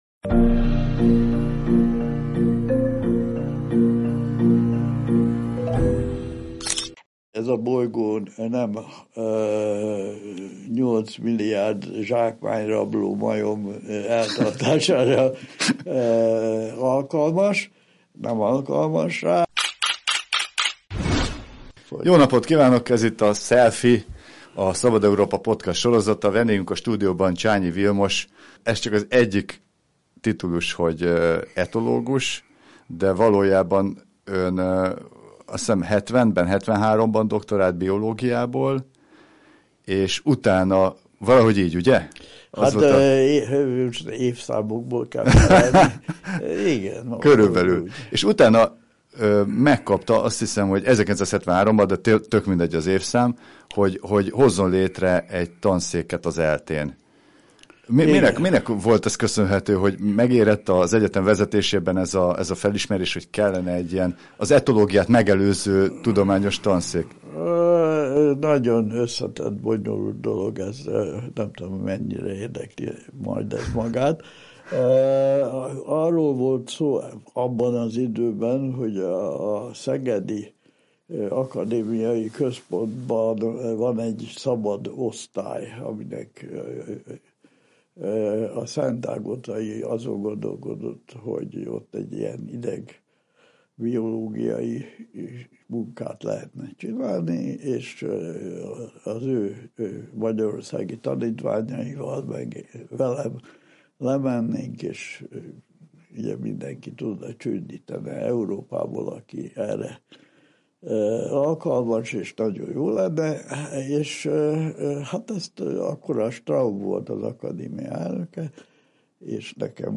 Vele beszélgettünk állatokról, emberekről, mesterséges intelligenciáról.